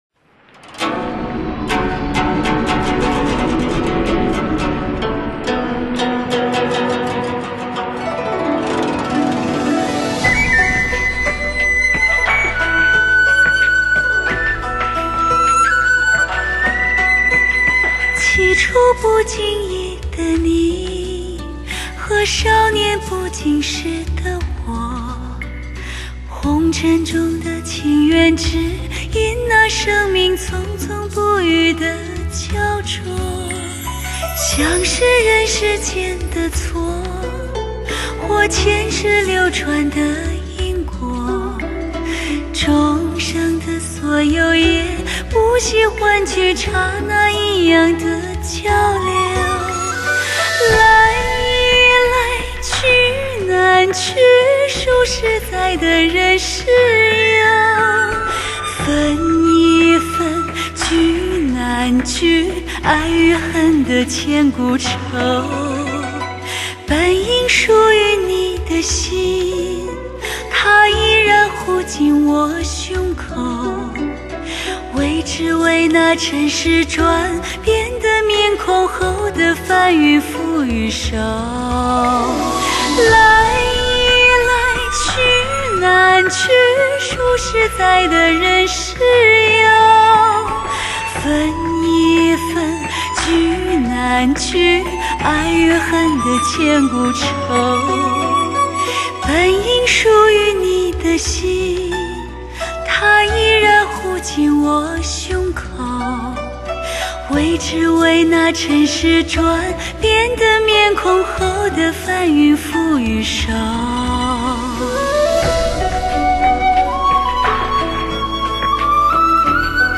HI-FI古装发烧女声
女声，征服你的耳朵！